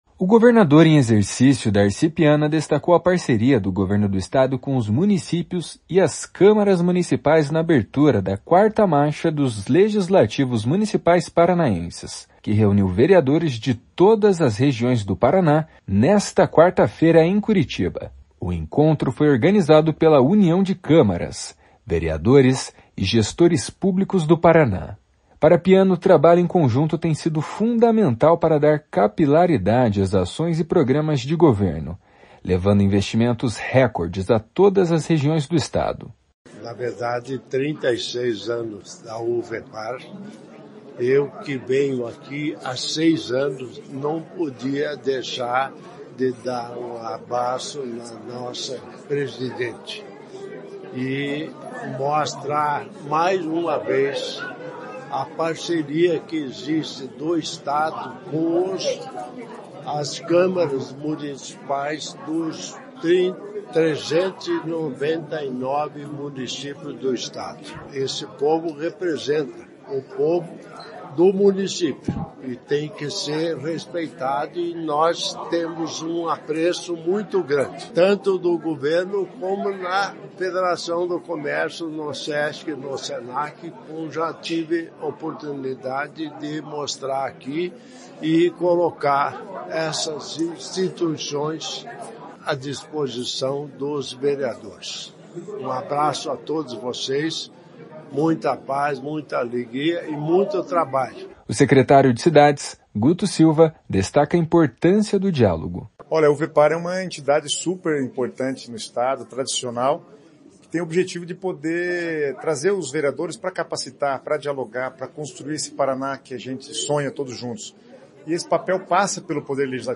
// SONORA DARCI PIANA //
O secretário de Cidades, Guto Silva, destaca a importância do diálogo. // SONORA GUTO SILVA //